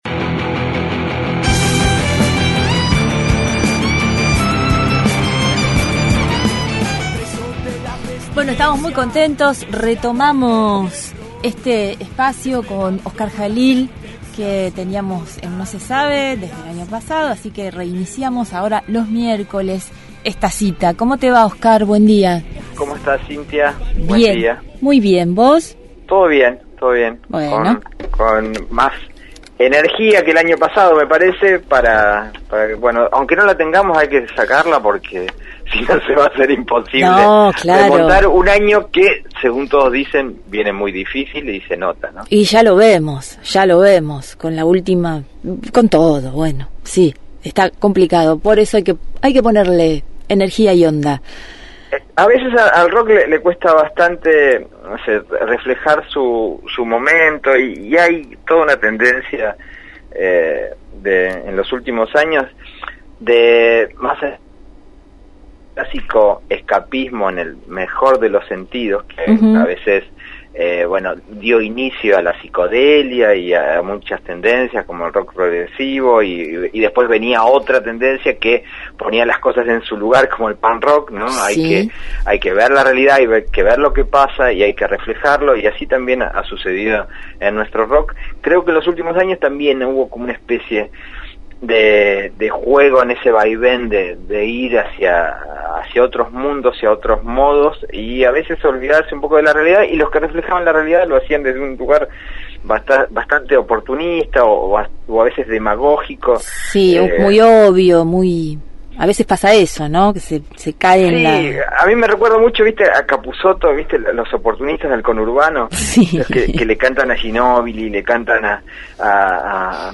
Columna musical